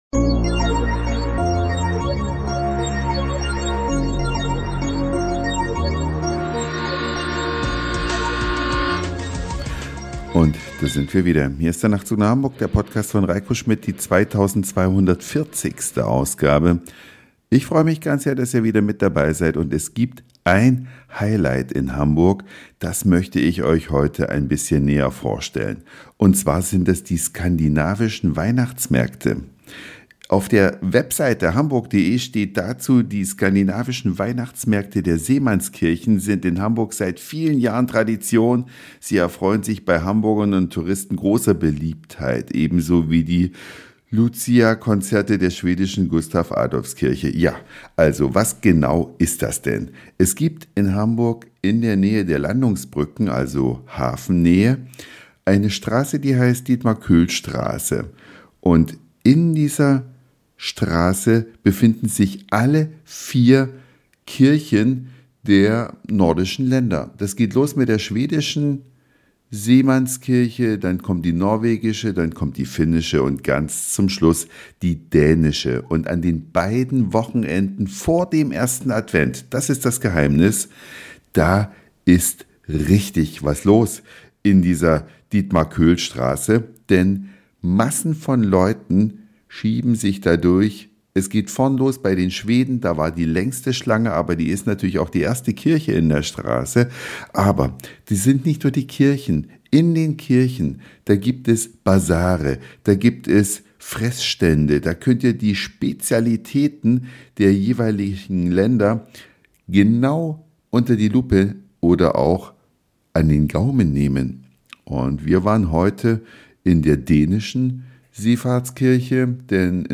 Eine Reise durch die Vielfalt aus Satire, Informationen, Soundseeing und Audioblog.
beim Glühwein (Glögg)-trinken am Nachbartisch, kurzes Gespräch